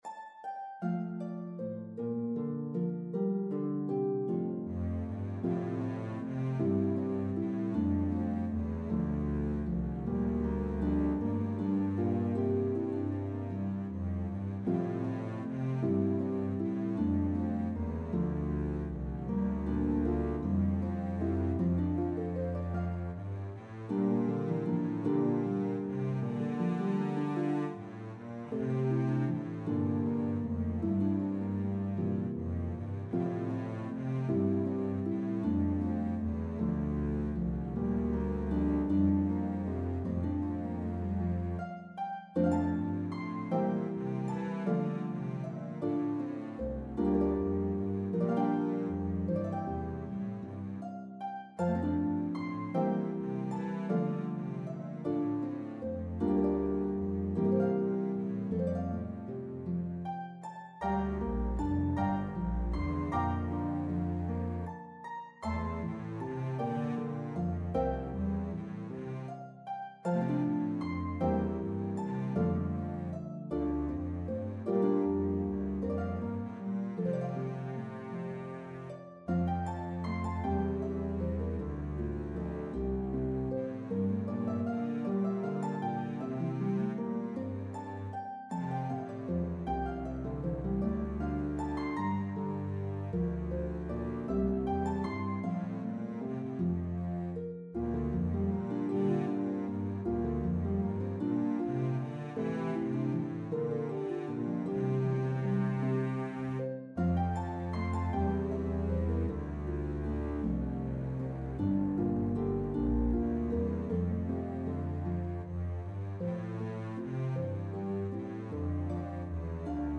Harp and Contrabass version